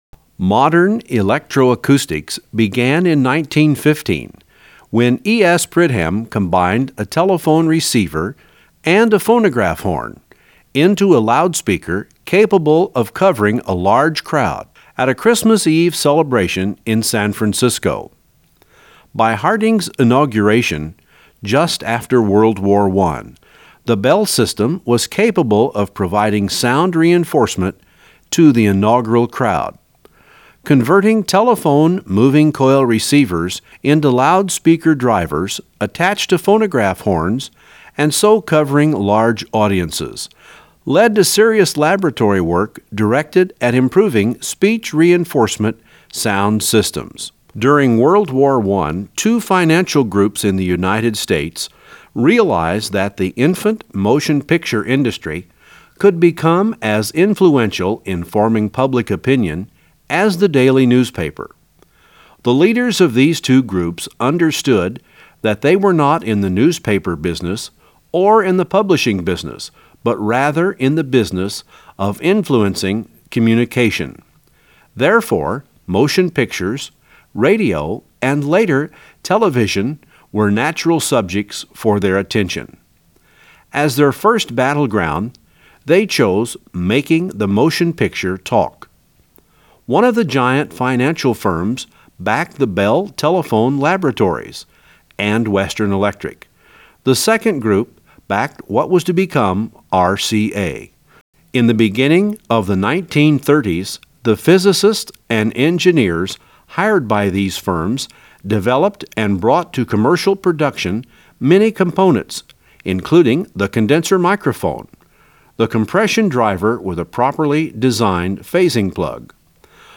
The left and right channels are identical, so only one channel is needed for mono playback. While not pristine by today’s standards, the noise floor is very low and there is very little reflected sound.
11_Lecture-48k.wav